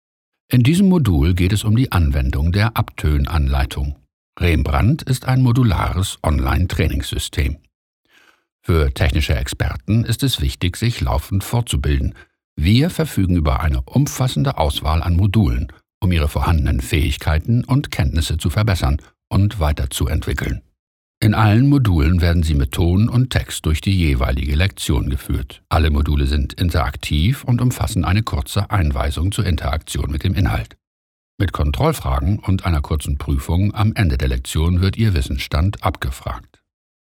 Deutscher und englischer Sprecher mit charismatischer, tiefer Stimme für Werbung, Film, Fernsehen, Synchronisation, Radio, Corporate Film, Audio Touren und E-Learning.
Sprechprobe: eLearning (Muttersprache):